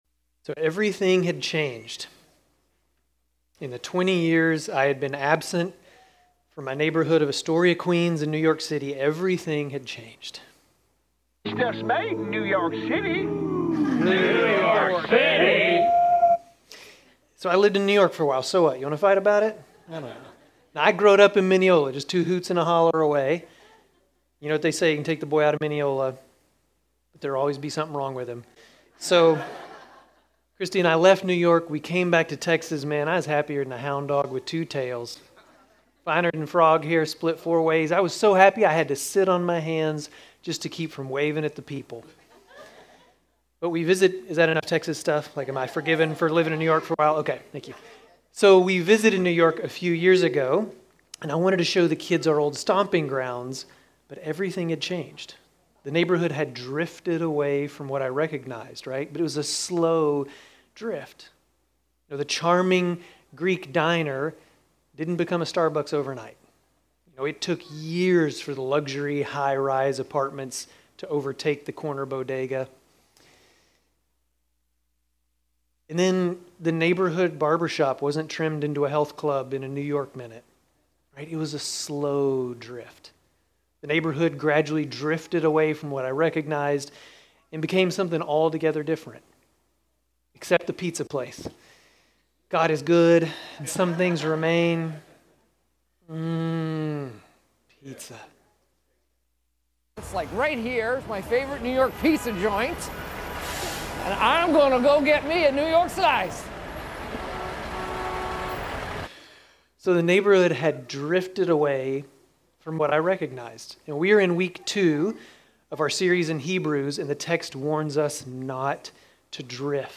Grace Community Church Dover Campus Sermons 9_21 Dover Campus Sep 22 2025 | 00:25:43 1x 00:00 / 00:25:43 Subscribe Share RSS Feed Share Link Embed